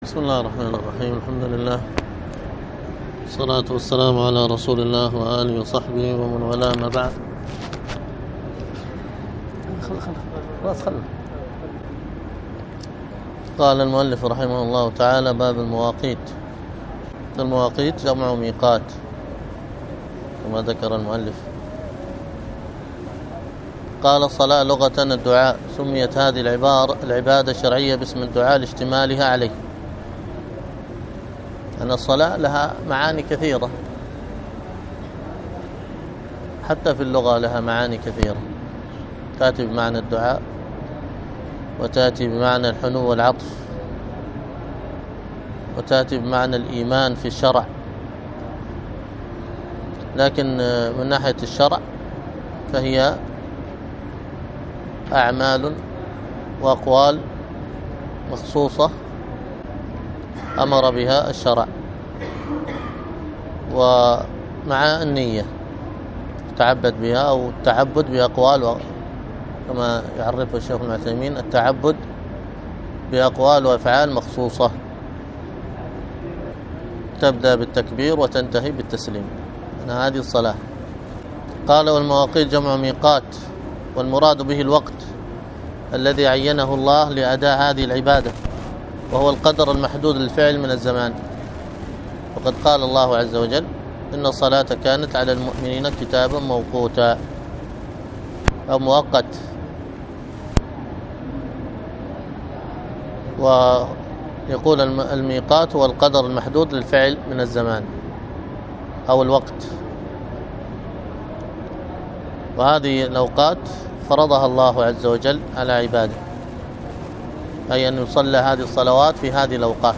الدرس في الصحيح المسند من دلائل النبوة 166، الدرس السادس والستون بعد المائة:(ومنها ما كان يلقبه به قريش قبل النبوة ... وشيخ الطبراني أحمد ابن القاسم بن مساور ترجمه الخطيب وقال وكان ثقة ).